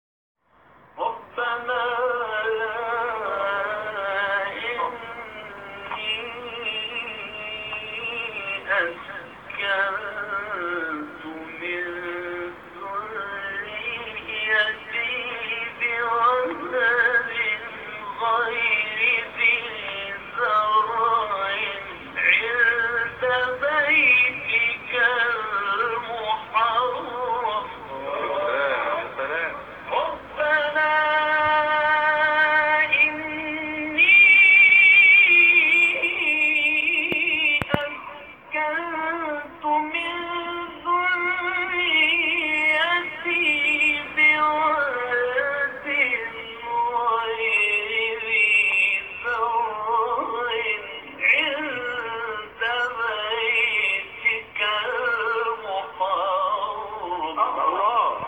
مقام صبا.m4a
مقام-صبا.m4a